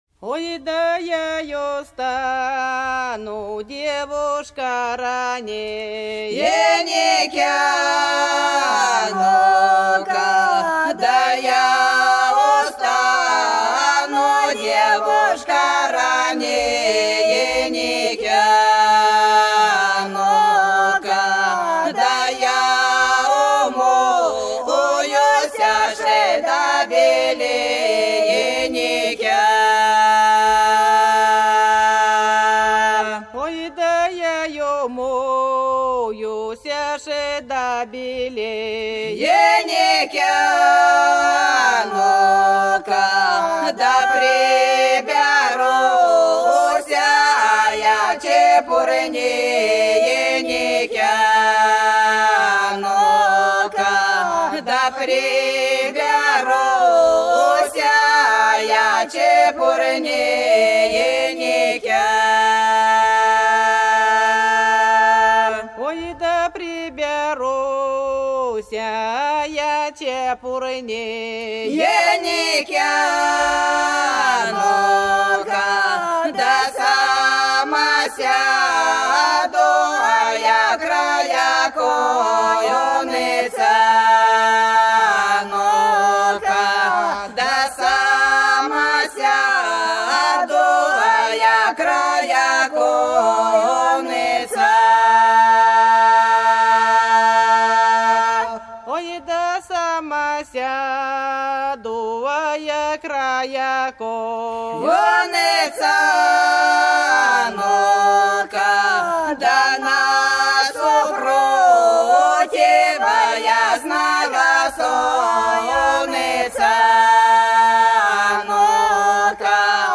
Долина была широкая (Поют народные исполнители села Нижняя Покровка Белгородской области) Я устану, девушка, раненько - протяжная